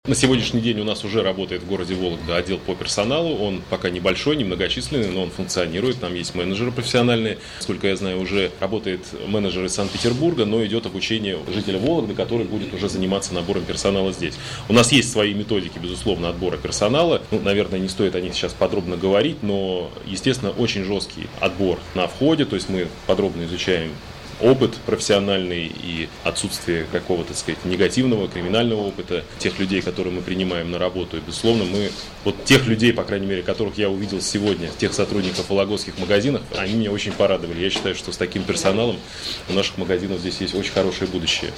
Как рассказал во вторник, 31 июля, на пресс-конференции в ИА «СеверИнформ»